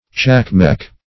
Tschakmeck \Tschak*meck"\